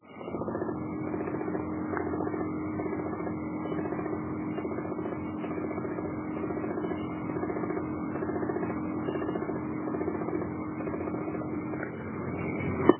1) Наружный блок трещит сам по себе
Cracks-on-its-own.mp3